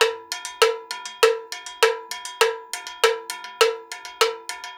Index of /90_sSampleCDs/USB Soundscan vol.36 - Percussion Loops [AKAI] 1CD/Partition B/03-100STEELW